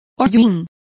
Complete with pronunciation of the translation of soot.